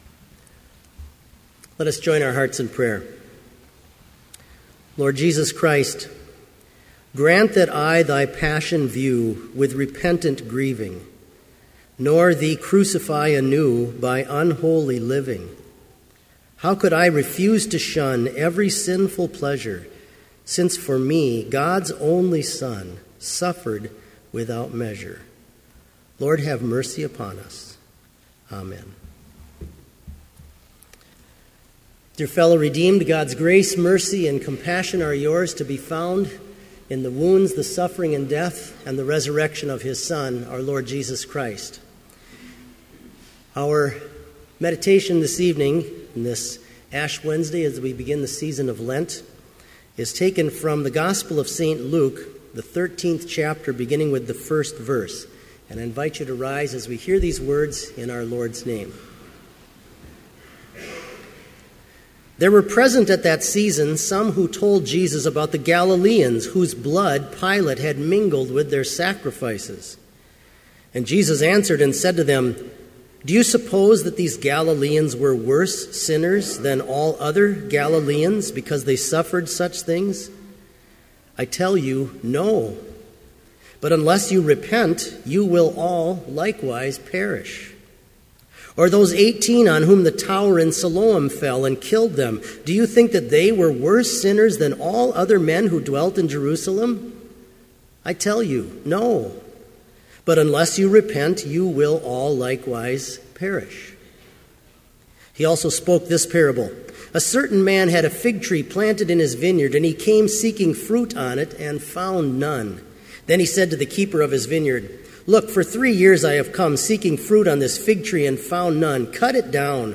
Sermon audio for Lenten Vespers - February 18, 2015